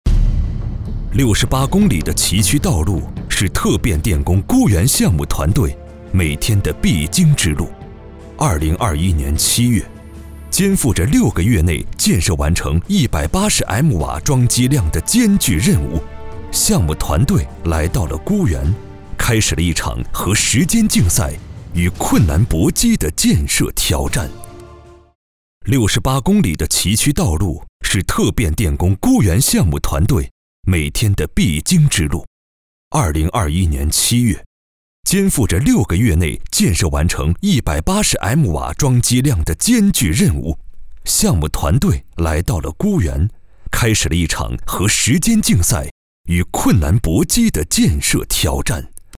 男2号配音师
专题片-男2-讲述风格.mp3